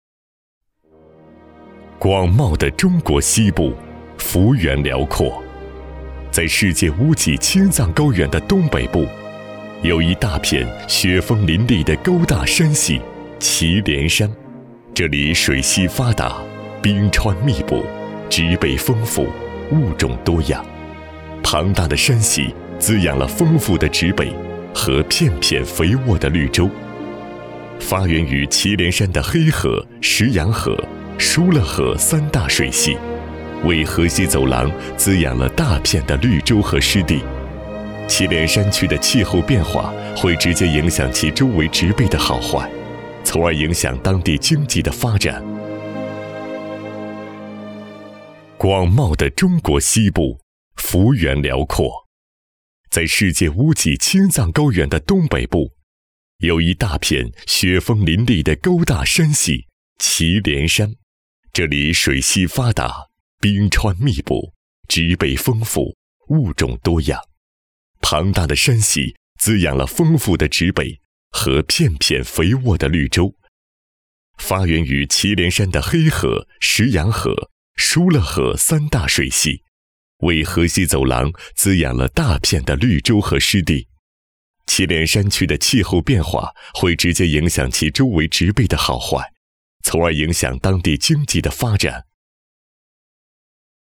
162男-大气恢宏
特点：大气浑厚 稳重磁性 激情力度 成熟厚重
风格:浑厚配音